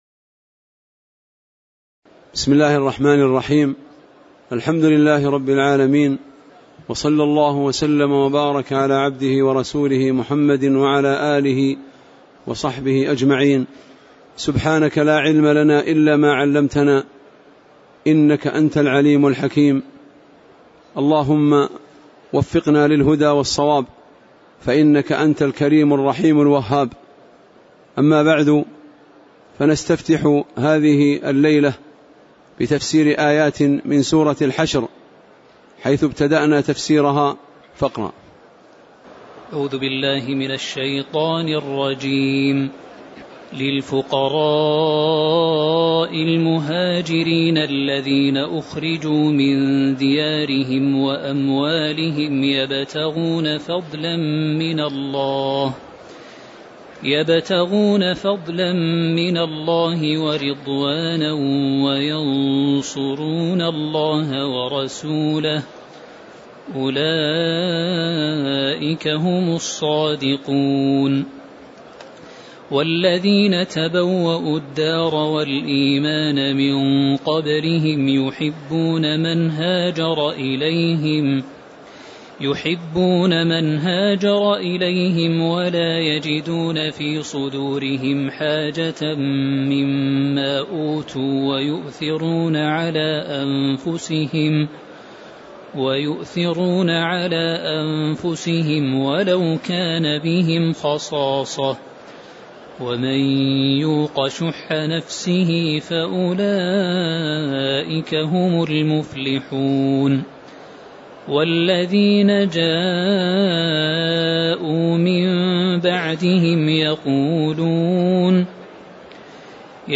تاريخ النشر ٦ رجب ١٤٣٨ هـ المكان: المسجد النبوي الشيخ